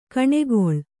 ♪ kaṇegoḷ